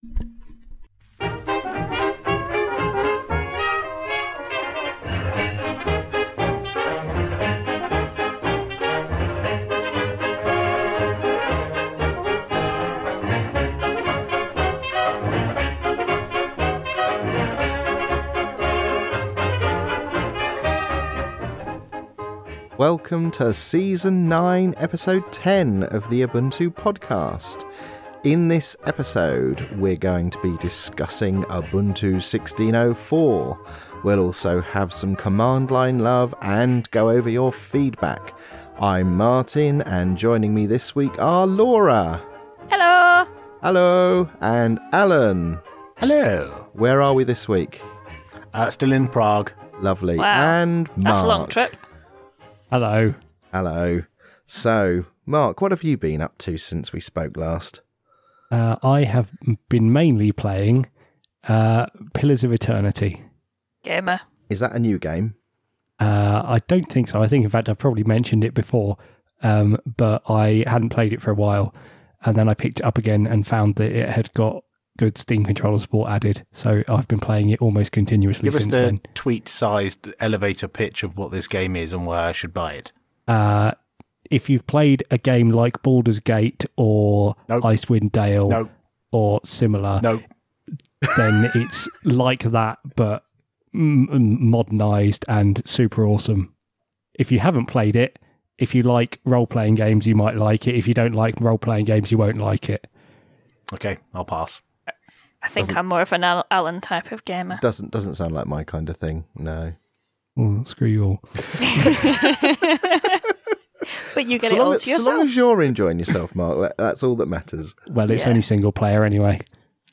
We’re here again, although one of us is still in Prague!